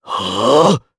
Clause_ice-Vox_Casting2_jp.wav